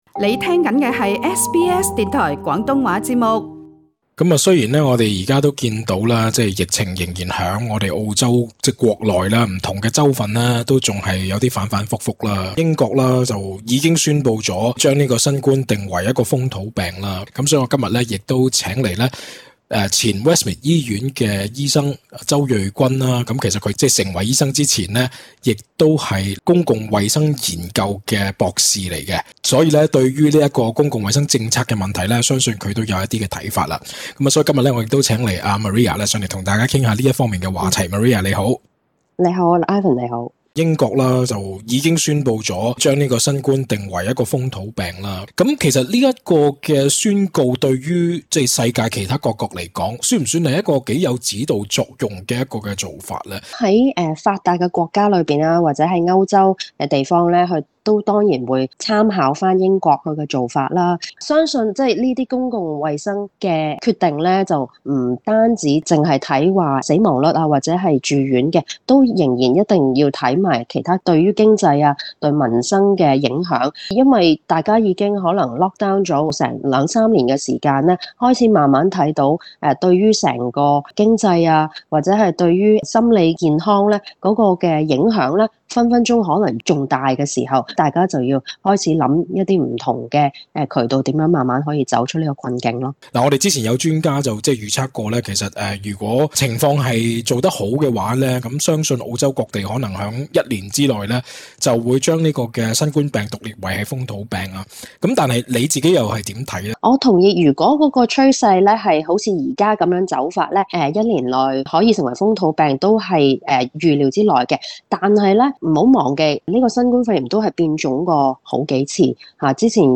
更多訪問詳情請收聽足本錄音。